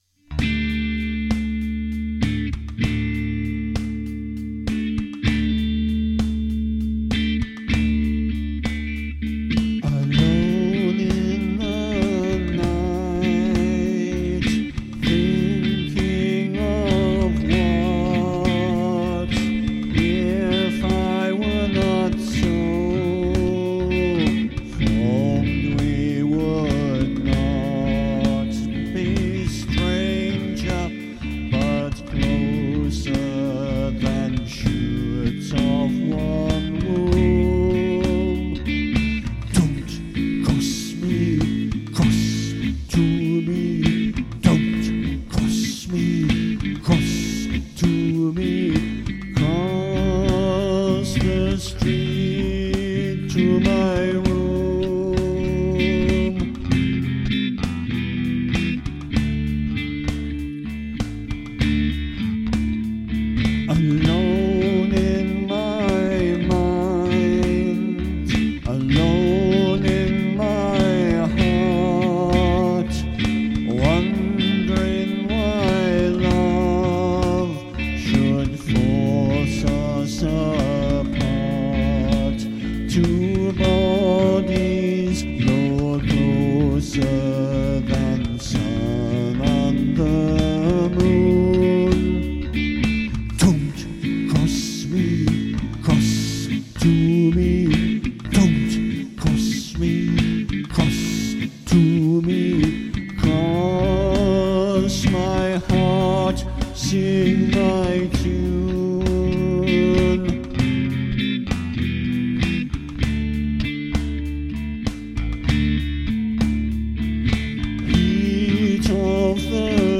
They’re a work in progress – I wanted to get something down in time for the book release – so currently demo quality, recorded in my home studio, with me doing all the parts.